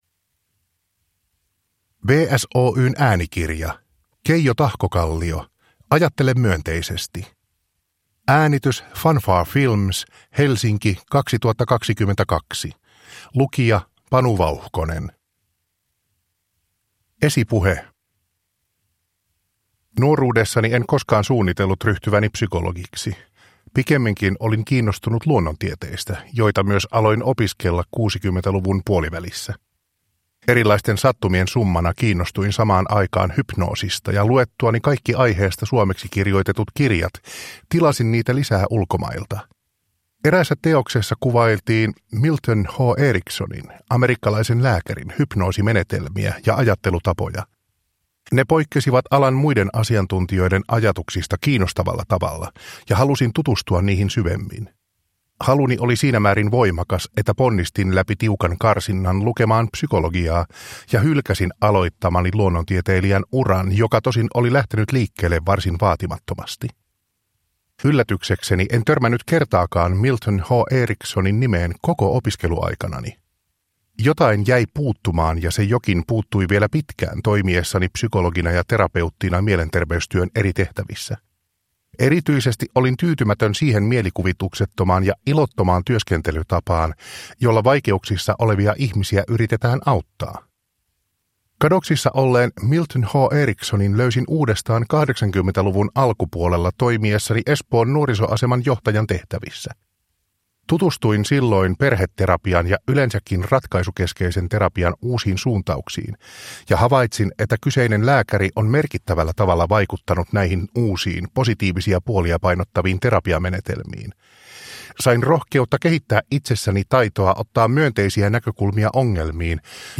Ajattele myönteisesti – Ljudbok – Laddas ner